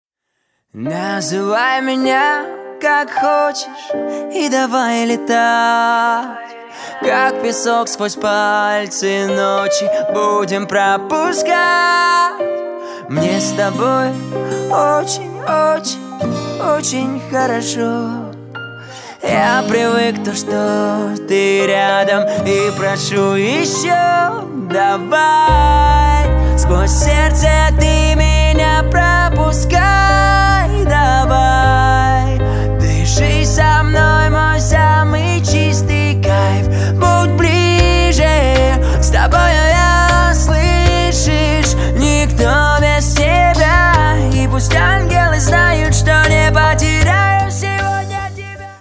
поп
мужской вокал
лирика
нежные